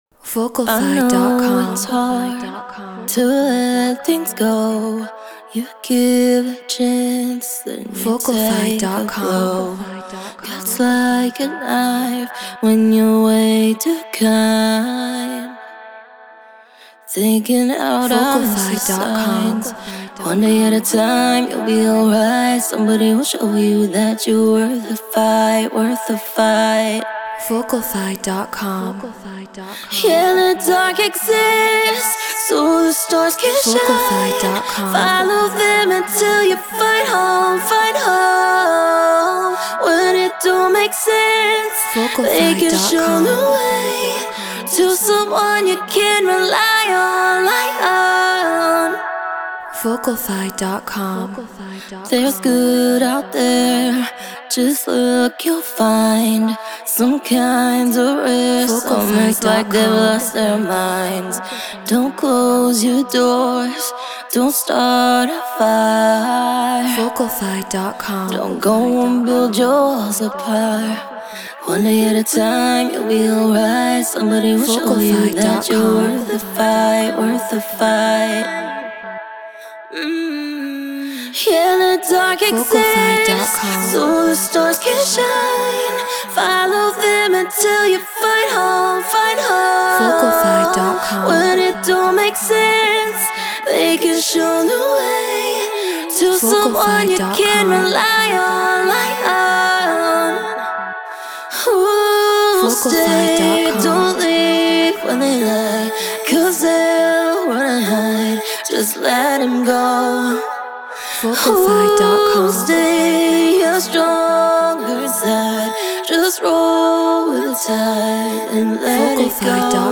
Pop 98 BPM Emaj
Human-Made